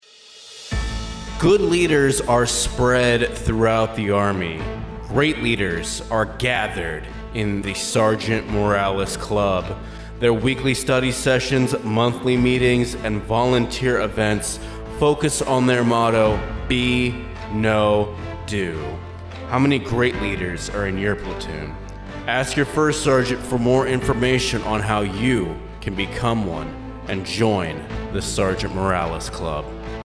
a radio commercial for the sergeant Morales club